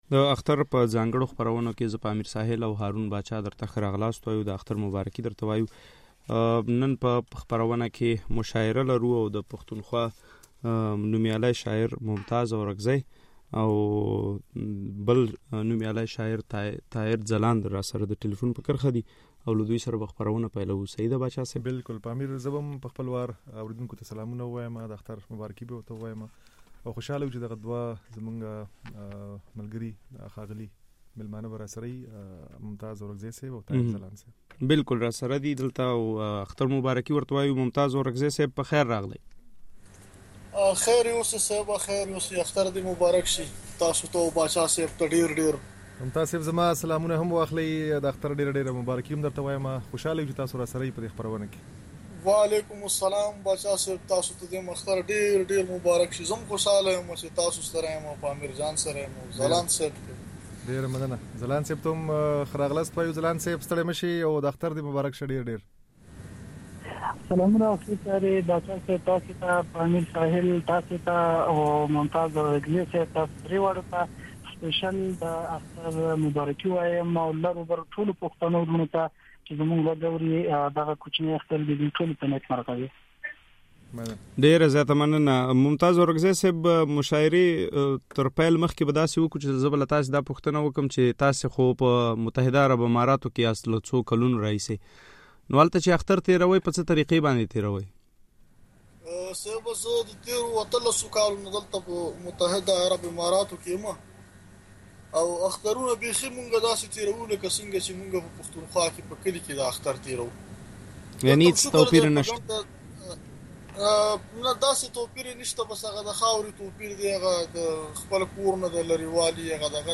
مشاعره